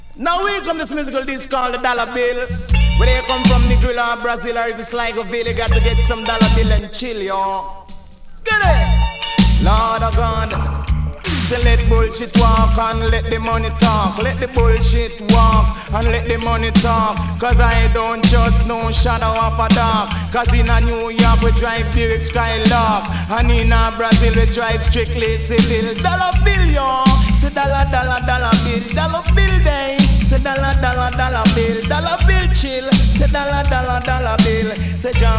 12"/Vintage-Dancehall